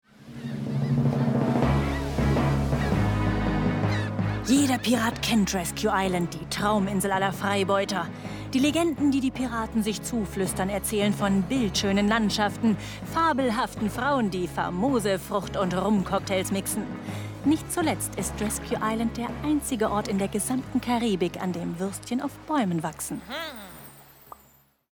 Sprechprobe: eLearning (Muttersprache):
german female voice over talent